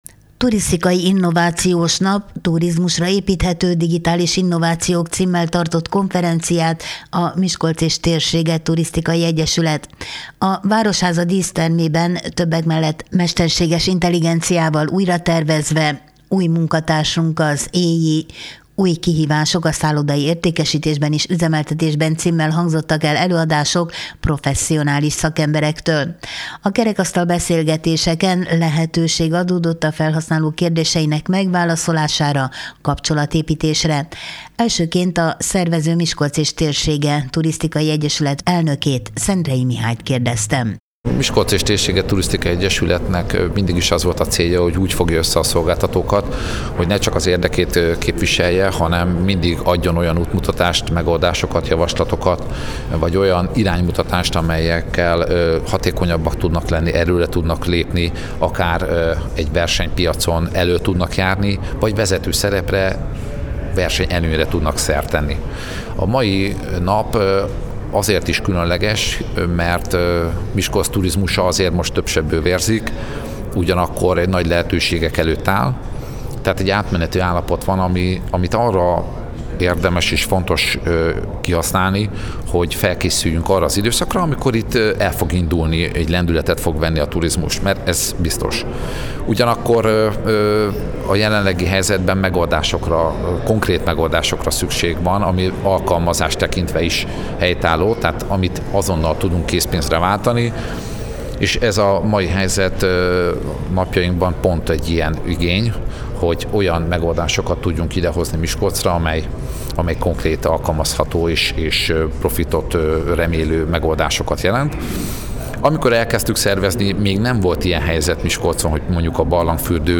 15-2_turisztikai_konferencia.mp3